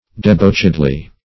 debauchedly - definition of debauchedly - synonyms, pronunciation, spelling from Free Dictionary Search Result for " debauchedly" : The Collaborative International Dictionary of English v.0.48: Debauchedly \De*bauch"ed*ly\, adv. In a profligate manner.